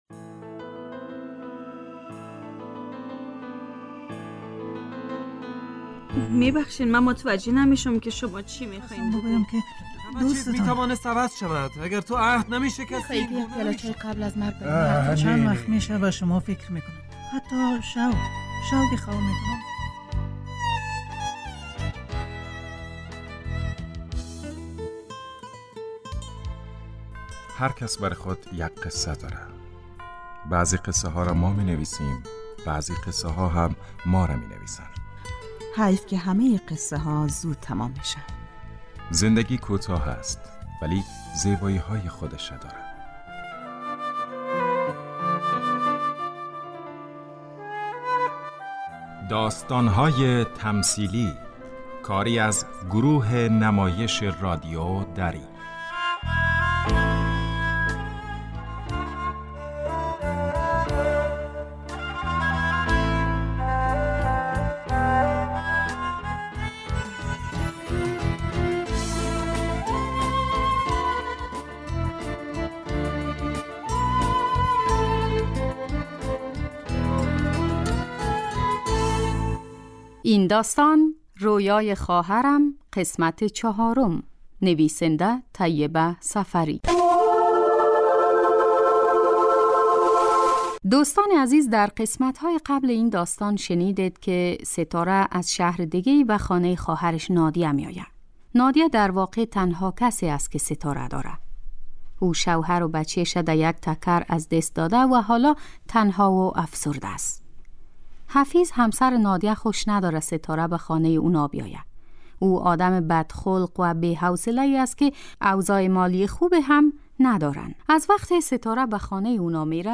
داستان تمثیلی -رویای خواهرم قسمت چهارم